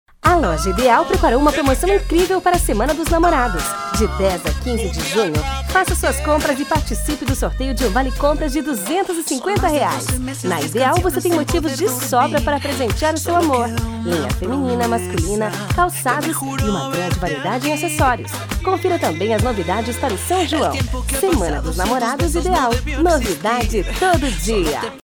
Especial publicitário
PROPAGANDA-IDEAL.mp3